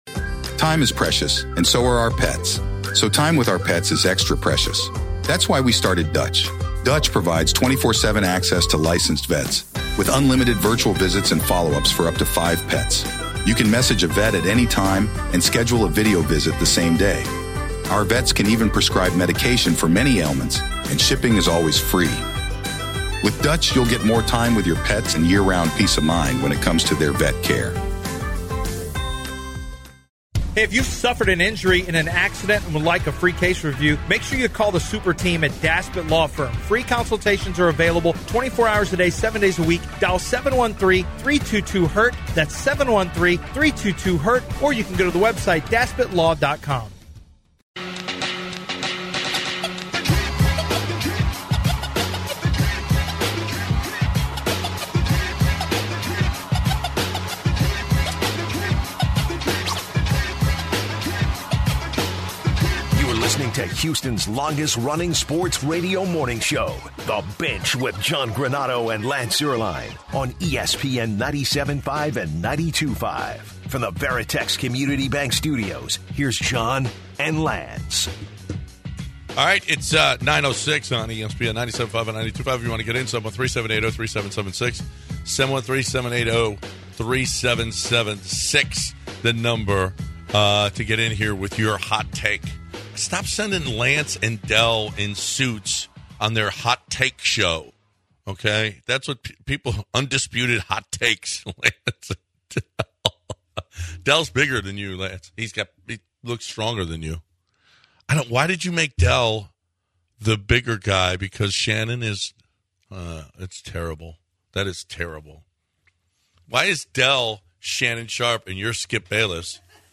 Listeners share their thoughts on the Astros and their trades and also player extensions. finally they finished this Segment with the News of the Weird.